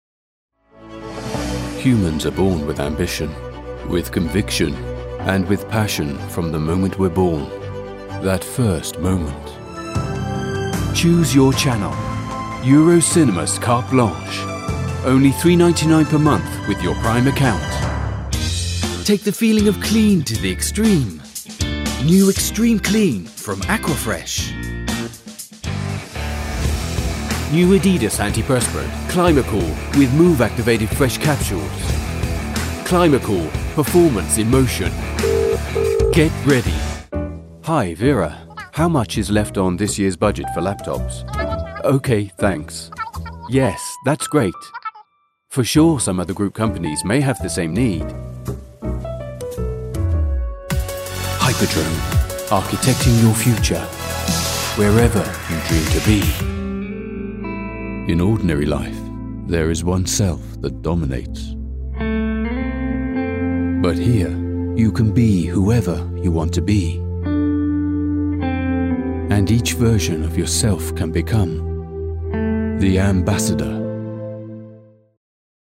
Warm signature voiceover, confident, engaging, conversational, versatile, commercial, corporate, informative, storyteller, classy
englisch (uk)
britisch
Sprechprobe: Werbung (Muttersprache):